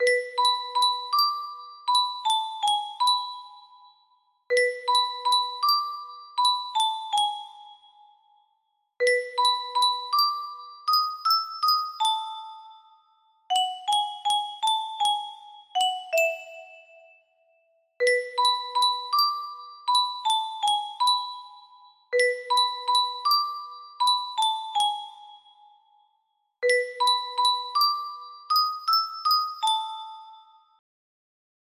Full range 60
music box form